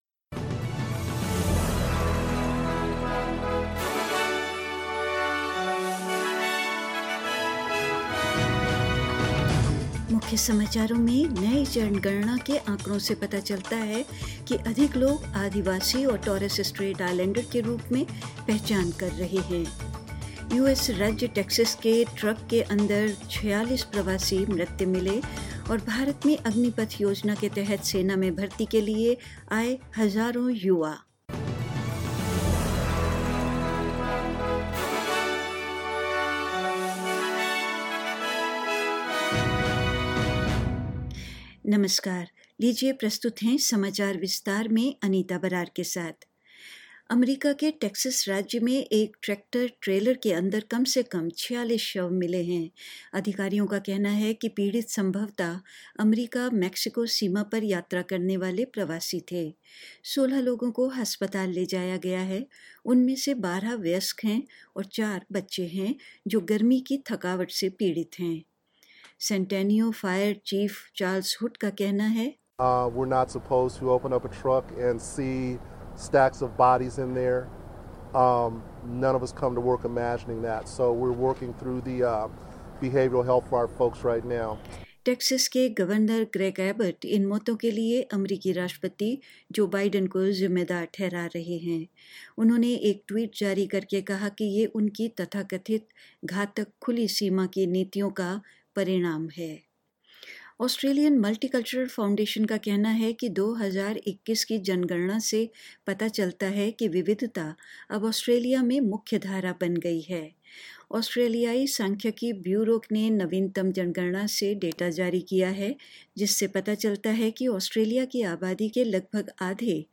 In this latest SBS Hindi bulletin: New census data reveals more people are indentifiying as Aboriginal and Torres Strait Islander, but underreporting remains an issue; Authorities in the U-S state of Texas say the bodies of migrants found in a truck were 'hot to the touch'; In India, a large number of youth registered for Agnipath scheme and more news.